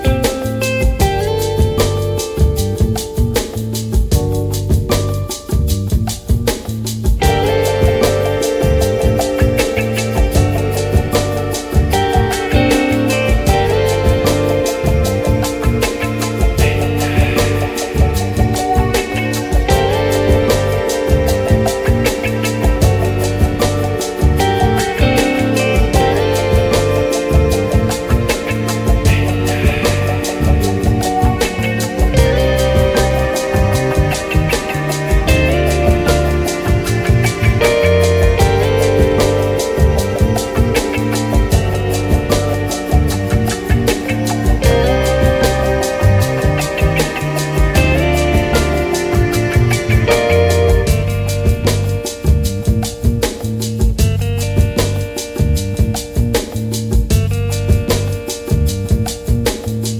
ジャンル(スタイル) NU DISCO / DISCO / FUNK